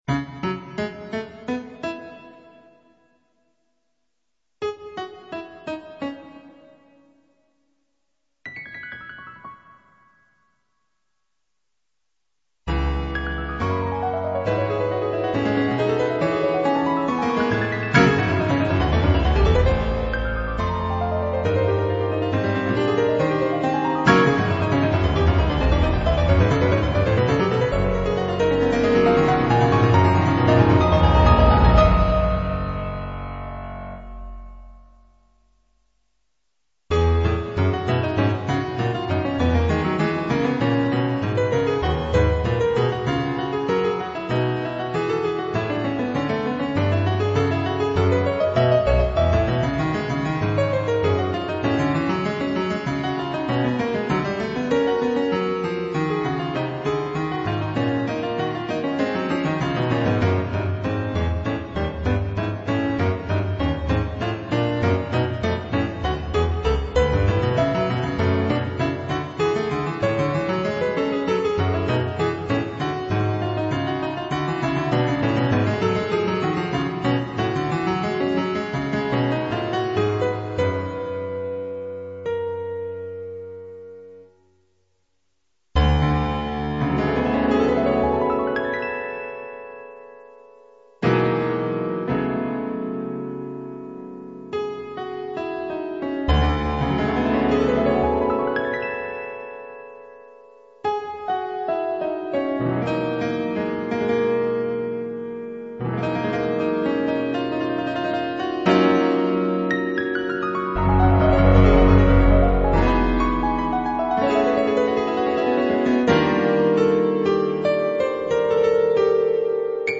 - Piano solo -
2) Pseudo-fuga ad una voce
5) Samba